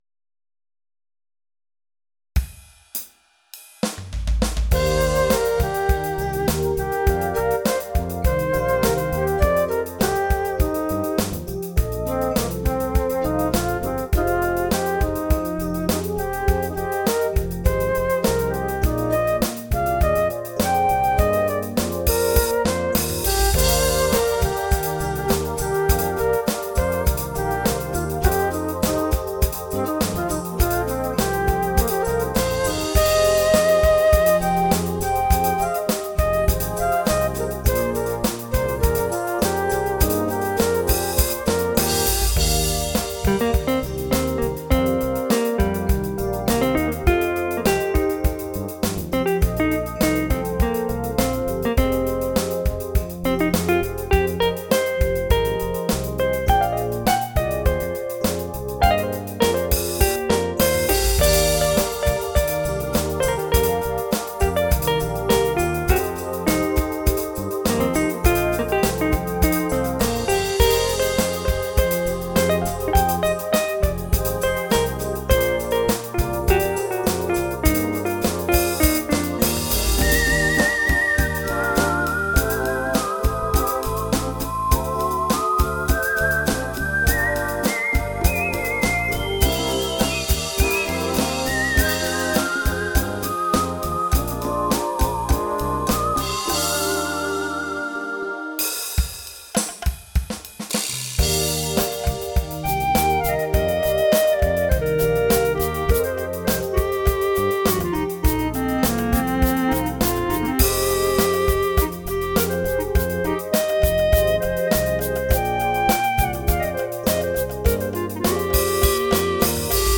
on a Roland FA-06 Music Workstation
Creation and Production done entirely on the workstation.